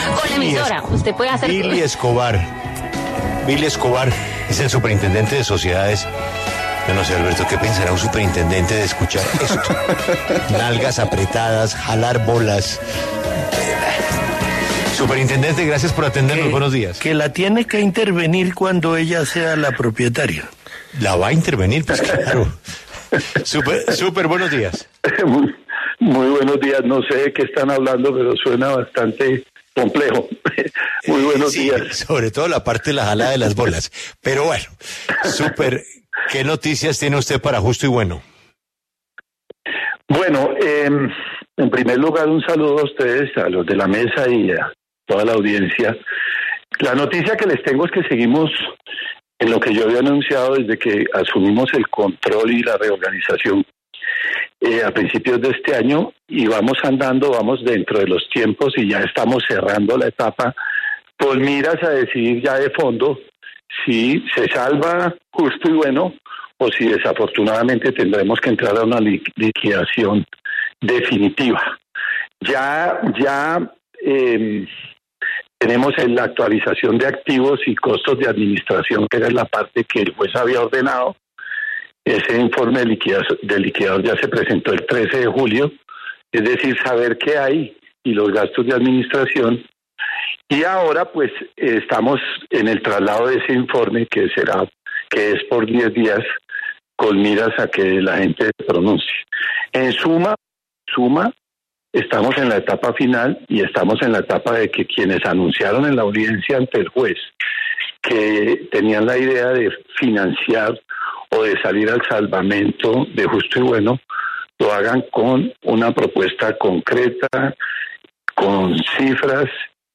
El Superintendente de sociedades, Billy Escobar, aseguró en W Radio, que el juez decidirá si acoge las propuestas que lleguen ese día por parte de acreedores e inversionistas.